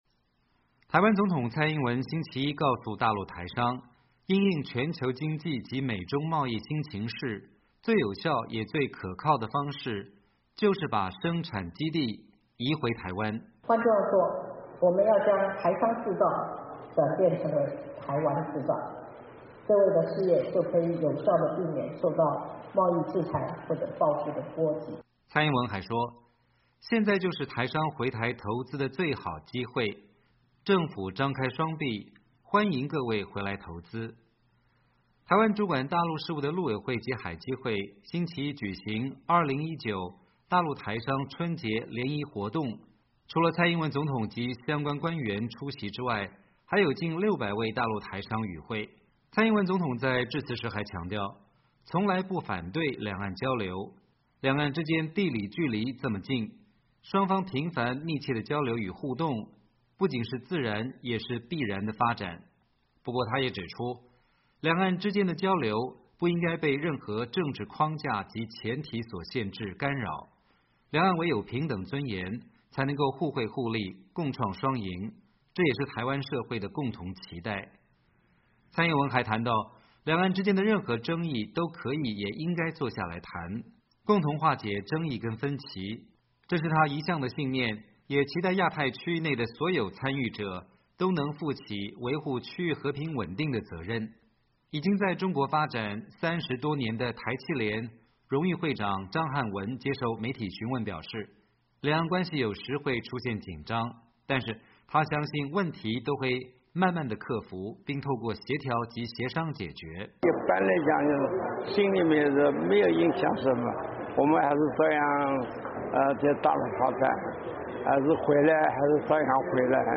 台湾总统蔡英文在2019大陆台商春节联谊活动上讲话